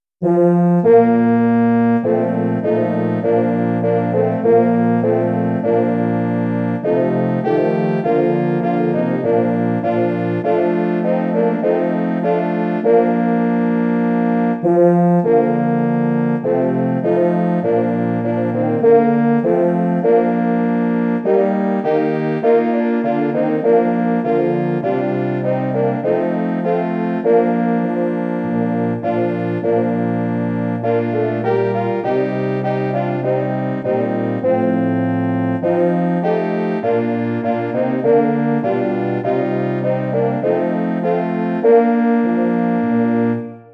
Volkslied
Bearbeitung für Hornquartett
Besetzung: 4 Hörner
German folk song
arrangement for horn quartet
Instrumentation: 4 horns